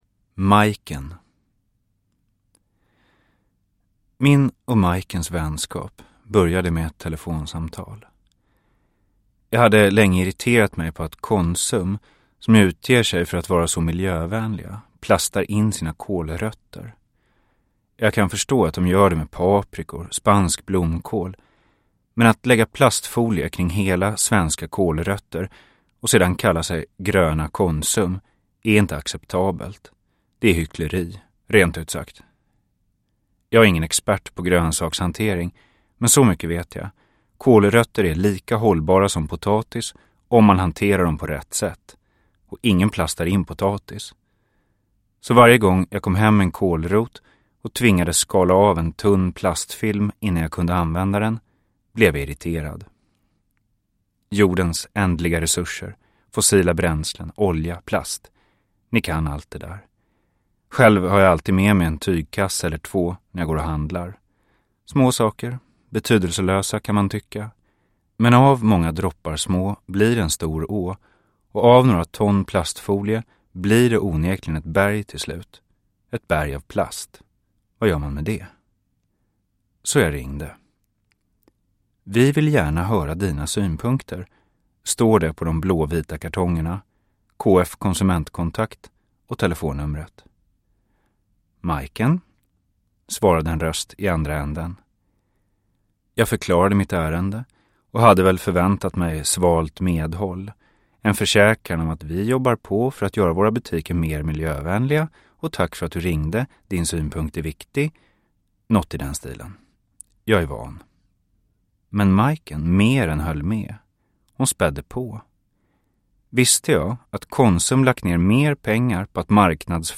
Majken – Ljudbok – Laddas ner